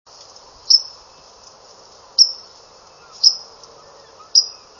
sparrow_WT_chinks_716.wav